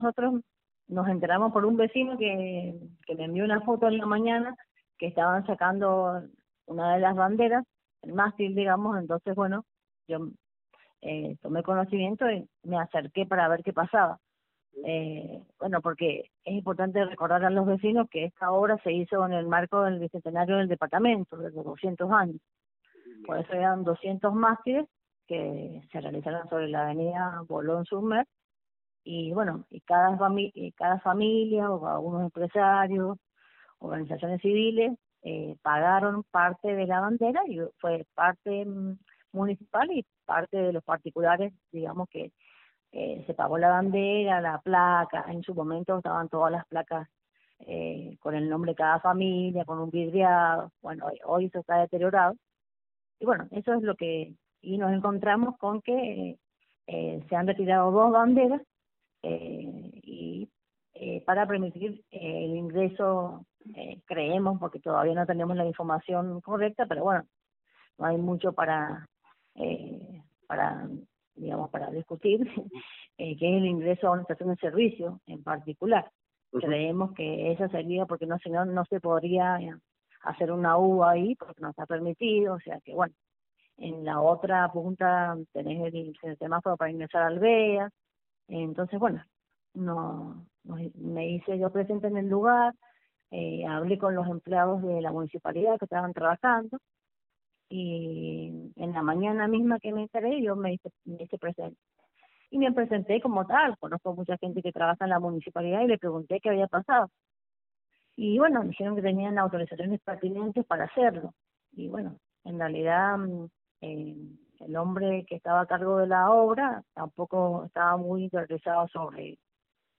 A continuación dejamos el audio completo de la entrevista realizada por nuestro periodista